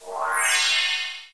button_success_done.ogg